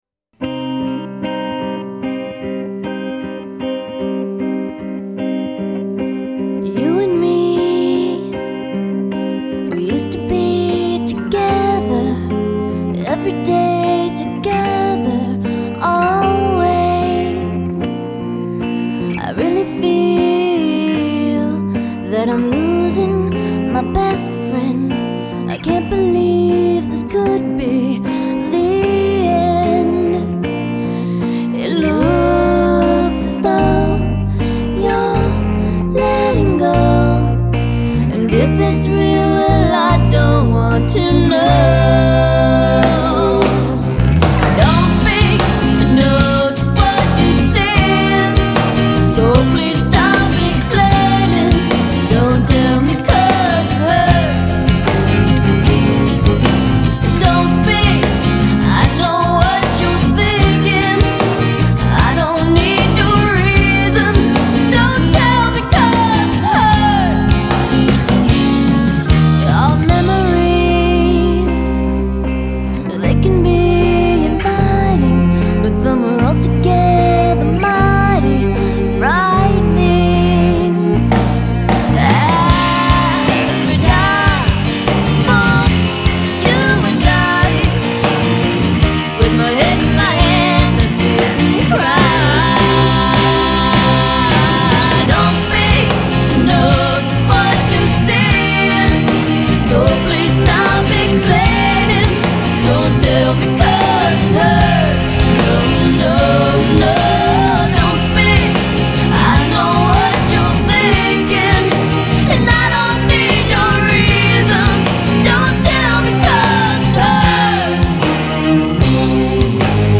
Там ремикс )